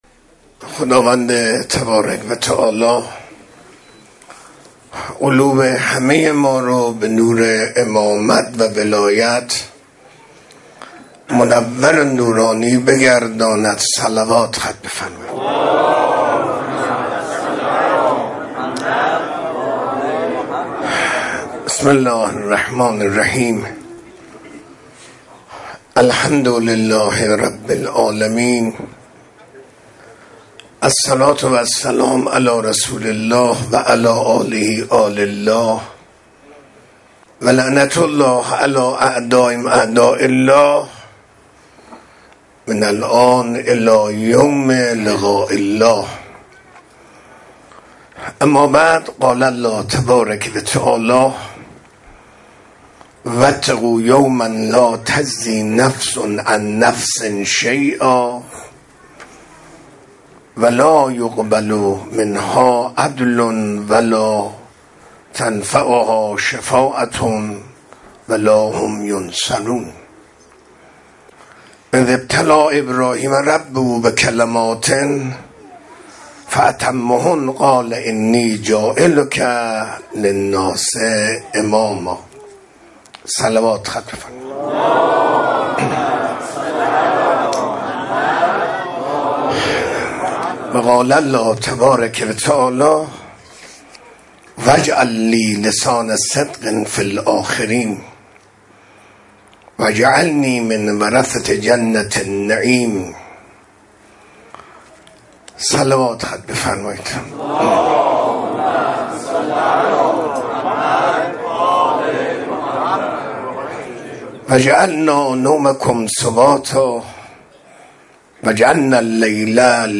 منبر 23 آبان 1403، منزل استاد